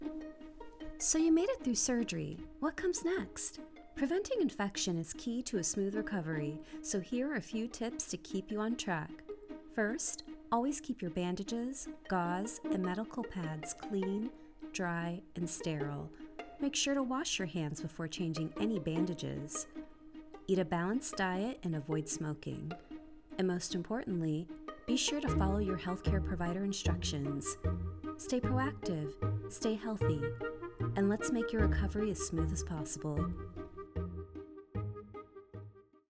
Voice-over artist with a warm, articulate, and soothing voice that brings calm and clarity to every project
Post-Op Surgery Explainer